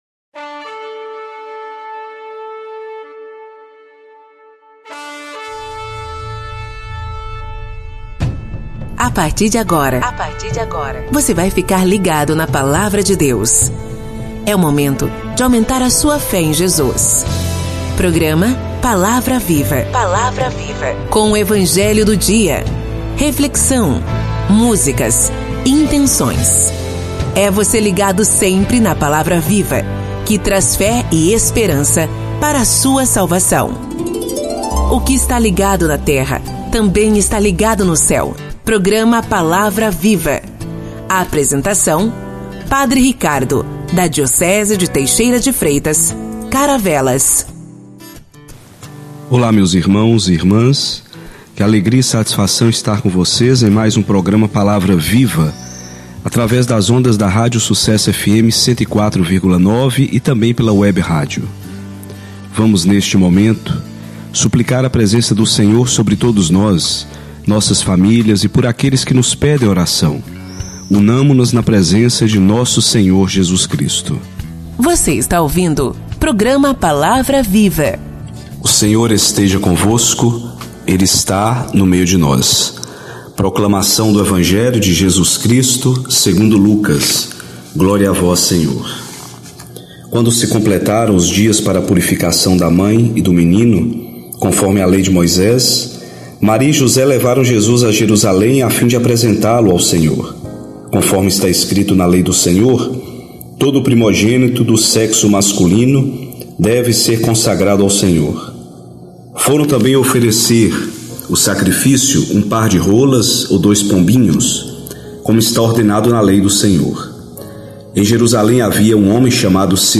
que conta com leitura do evangelho do dia, reflexão, intenções e pedidos de oração de fiéis, músicas, e acontecimentos semanais na Diocese.